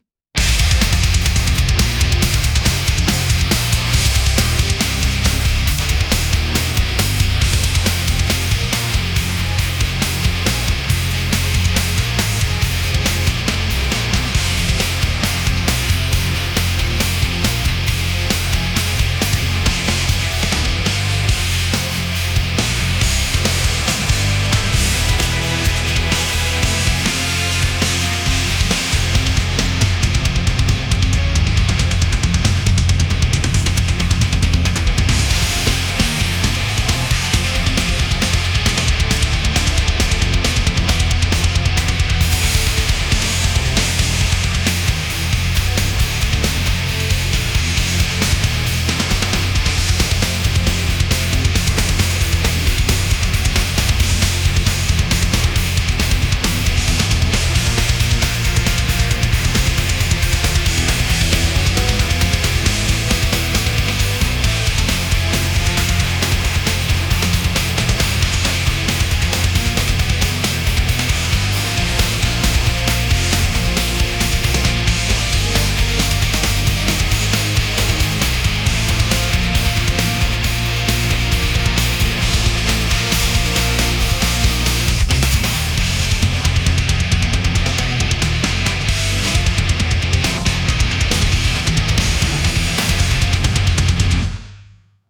music
ente_evil_1.wav